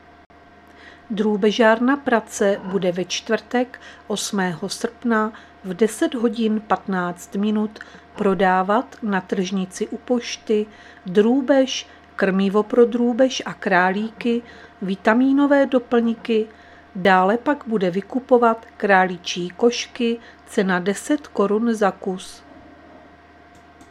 Zařazení: Rozhlas